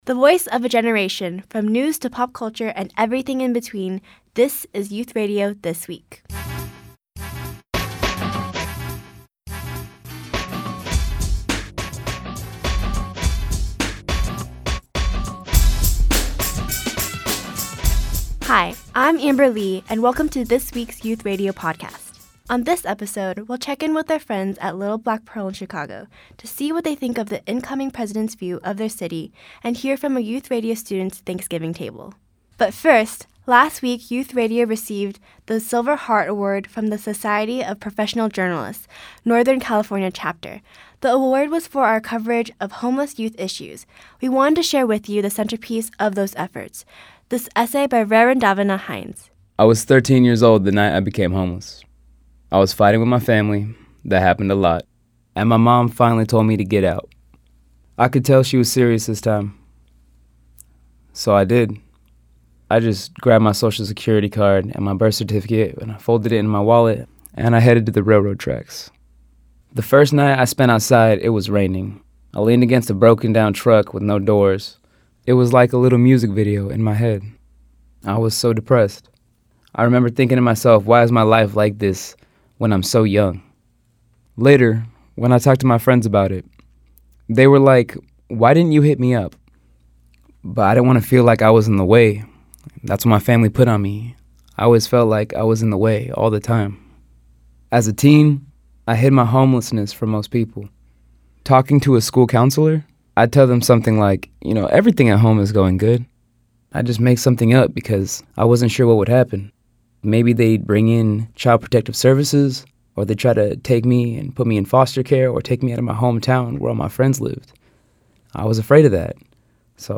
podacst_thnxgivingIn this week’s Youth Radio podcast, we explore the meaning of “home,” from a raw conversation with Chicago teens as they compare their perception of their home city with the President-elect’s version, to our award-winning story of a young man who became homeless at age 13, to a teen who is hungry (literally) for what she associates with home on Thanksgiving.